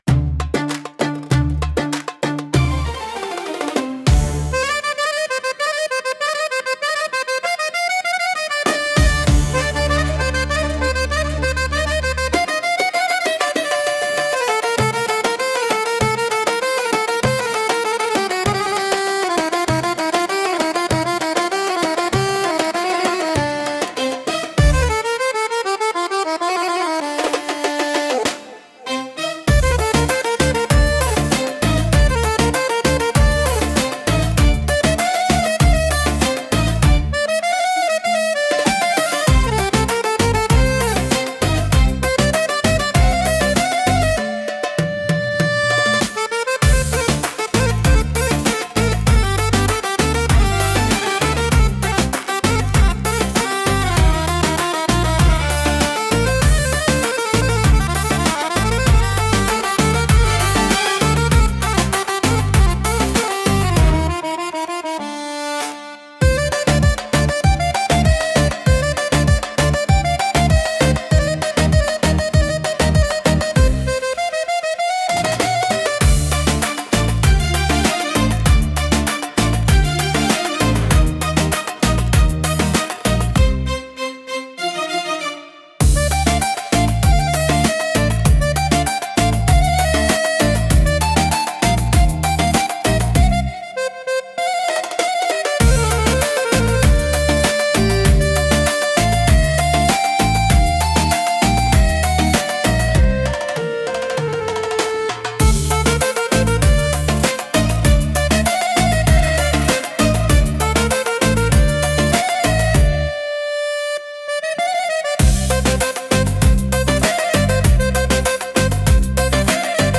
独特のメロディとリズムで聴く人の感覚を刺激します。